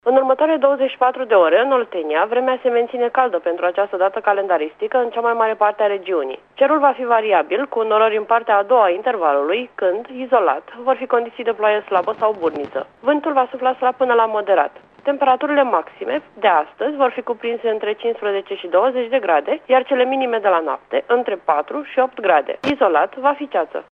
Prognoza meteo pentru 5 noiembrie
5-NOIEMBRIE-PROGNOZA-AM.mp3